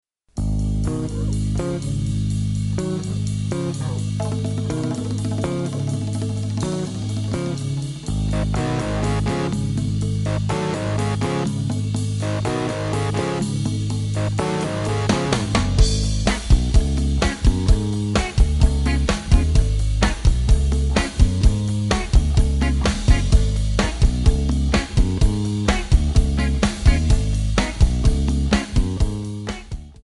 Backing track files: Rock (2136)
Buy With Backing Vocals.